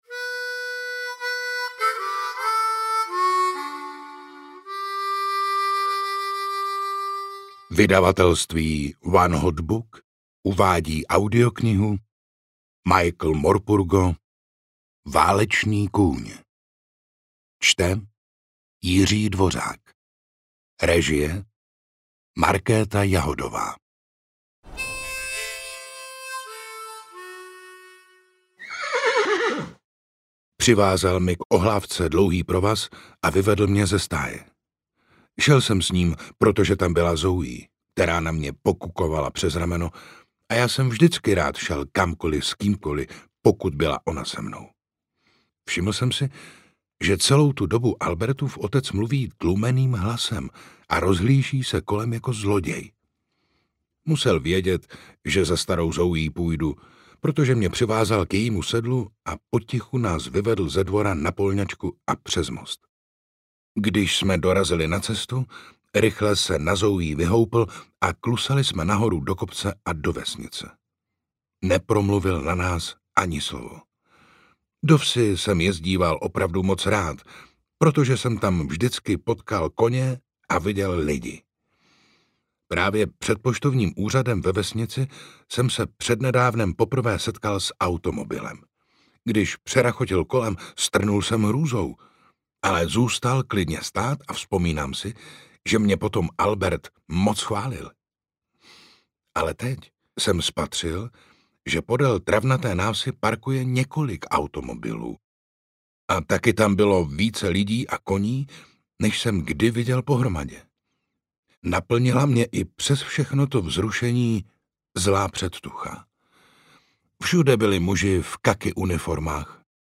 Audiobook
Read: Jiří Dvořák